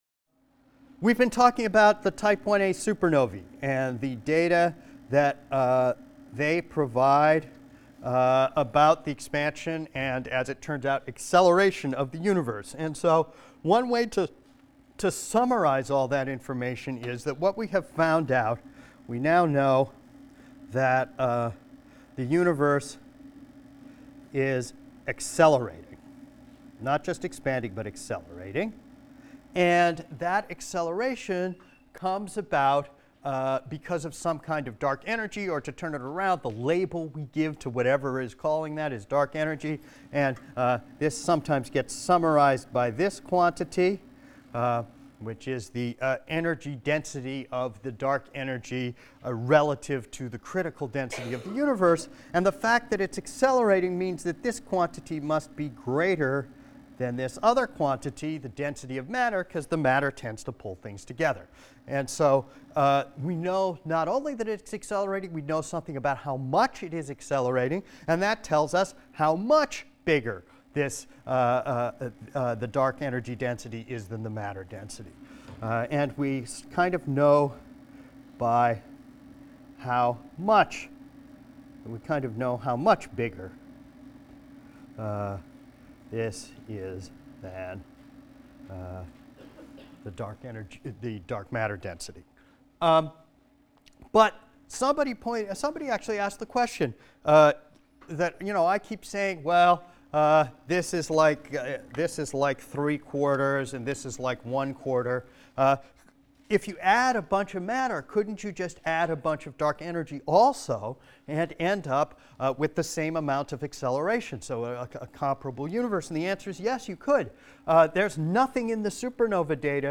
ASTR 160 - Lecture 23 - Other Constraints: The Cosmic Microwave Background | Open Yale Courses